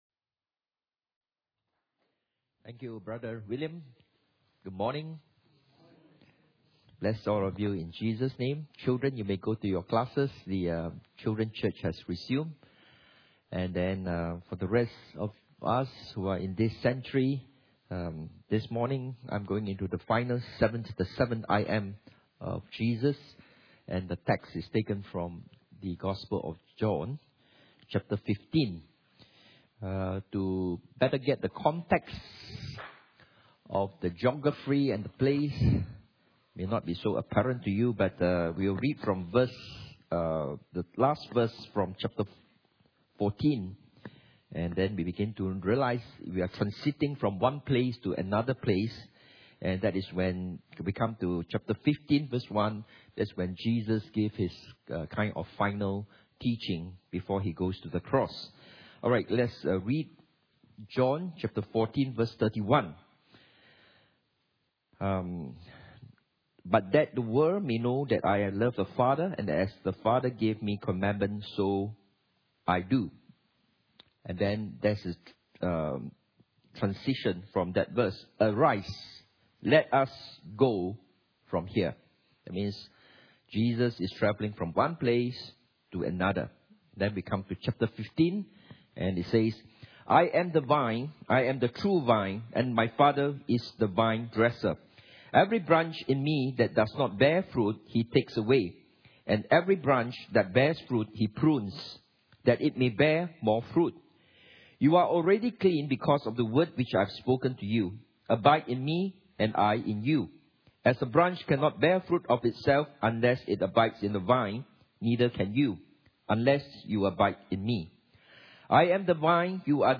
The 7 I AM's of Jesus Service Type: Sunday Morning « The 7 I AM’s of Jesus P7